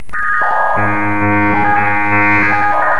computer loading part 2
Category 🗣 Voices
ambient artificial atmospheric computer freaky horror old software sound effect free sound royalty free Voices